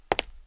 FueraDeEscala/Assets/Game Kit Controller/Sounds/Foot Steps/Concrete/concretFootstep05.wav at ff41df25cdd0220a8eeb9333d2e2aa5c8bdf9b62
concretFootstep05.wav